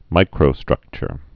(mīkrō-strŭkchər)